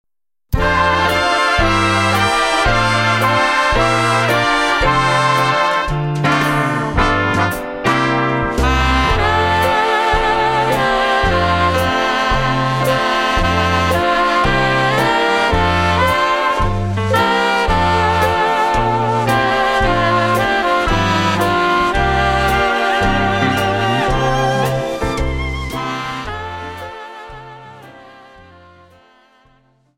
FOX-TROT:28(4+64)